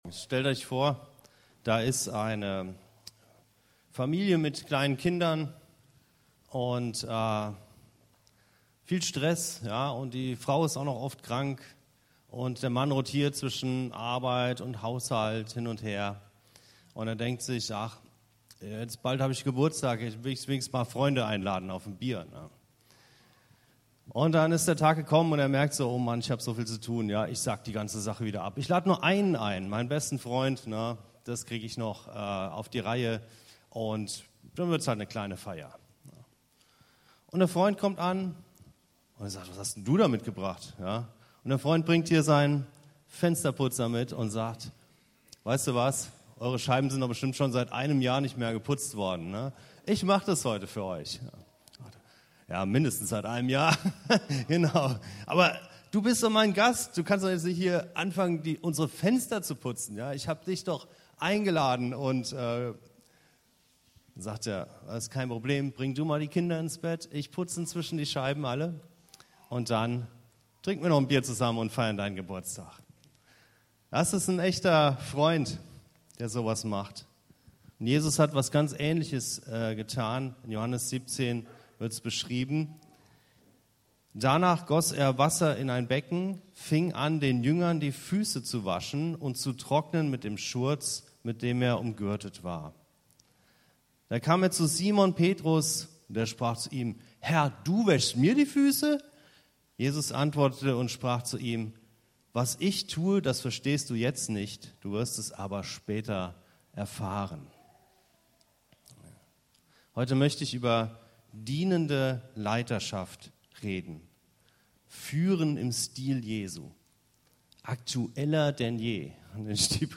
Was bedeutet "Führen im Stil Jesu"- und warum ist das heute so aktull wie nie? In dieser Predigt geht es um dienende Leiterschaft als Kontrast zu Machtmissbrauch, Leistungsdruck und Selbstdarstellung.